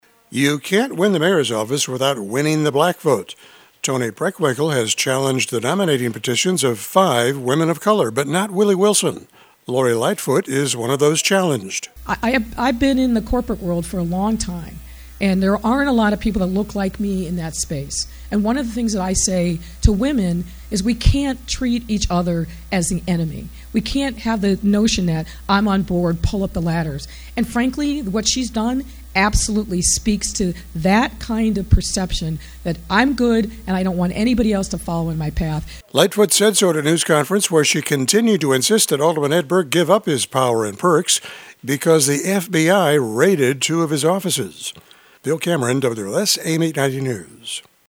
Lightfoot said so at a news conference where she continued to insist Ald Ed Burke give up his power and perks because the FBI raided two of his offices.